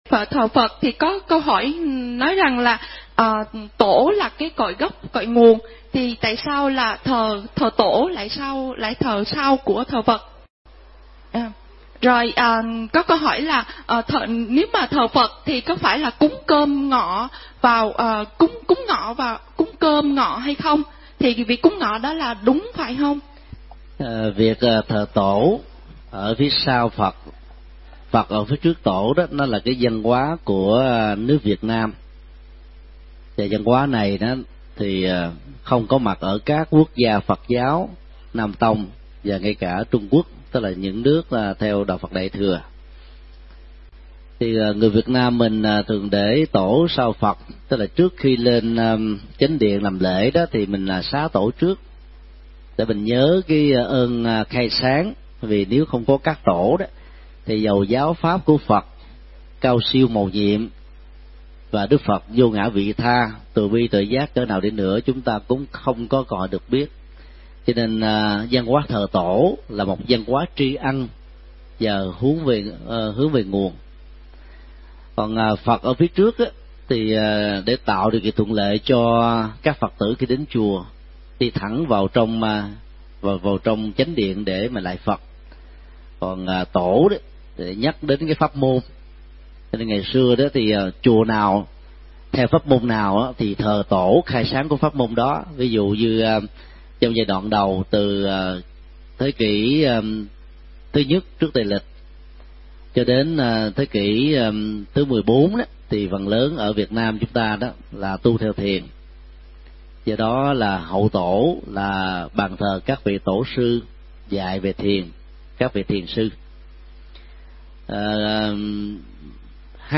Vấn đáp: Văn hóa thờ Tổ, cúng cơm Phật vào giờ trưa